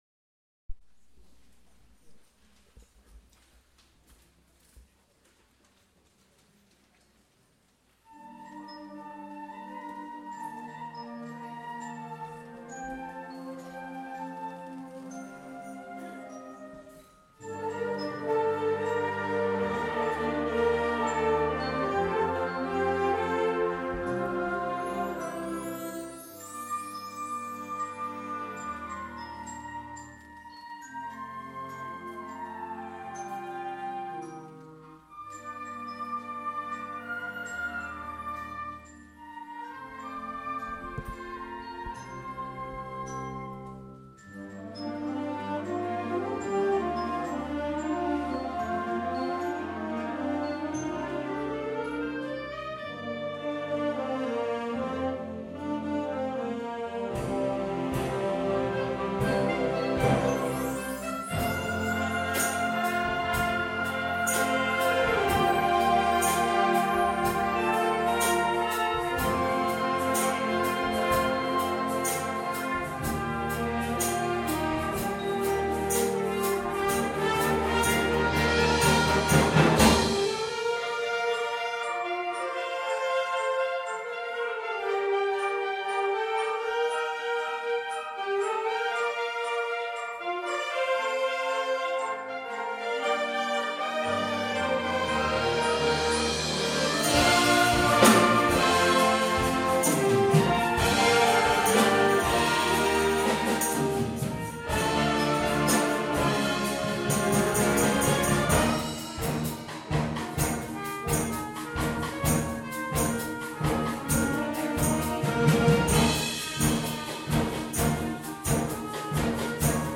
A Concert of Wind, Brass and Percussion, April 2015